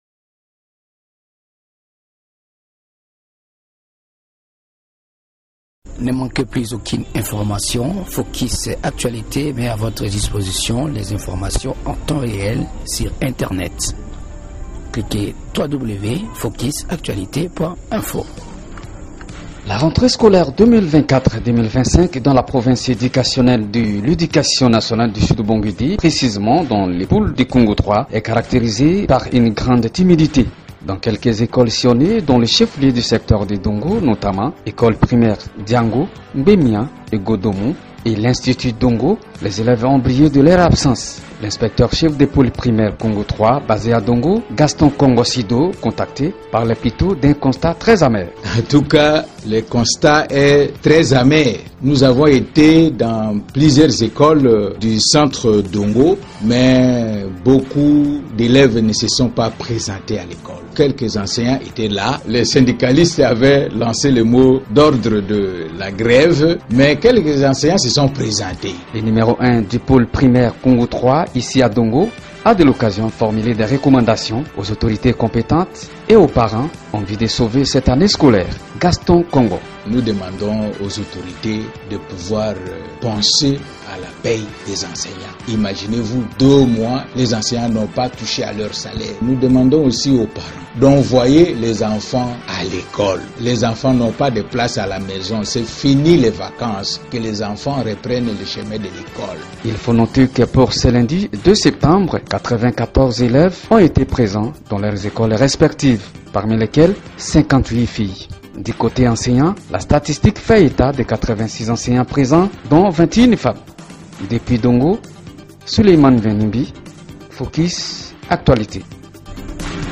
Reportage sur la rentrée scolaire dans le secteur de Dongo, province du Sud-Ubangi. by